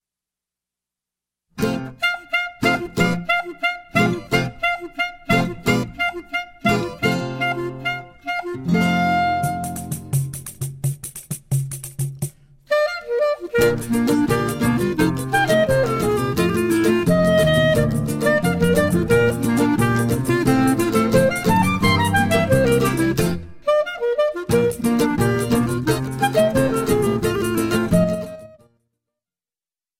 Choro ensemble